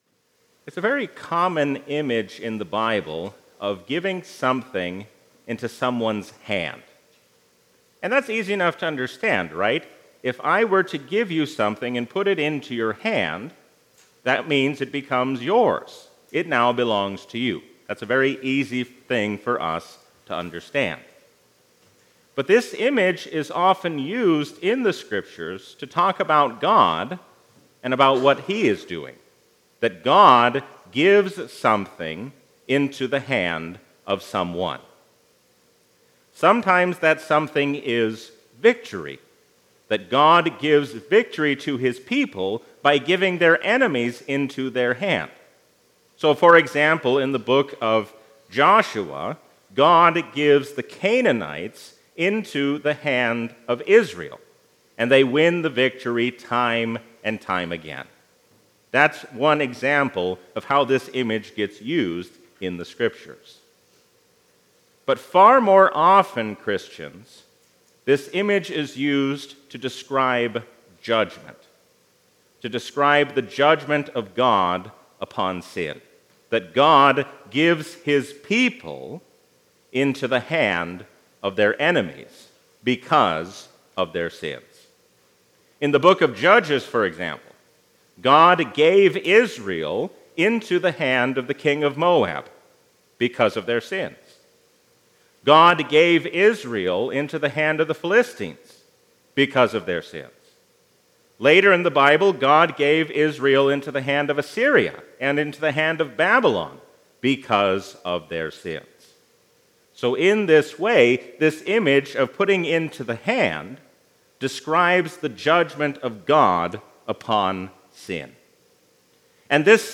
A sermon from the season "Trinity 2024." If God can forgive sinners like King Manasseh, He can also forgive you.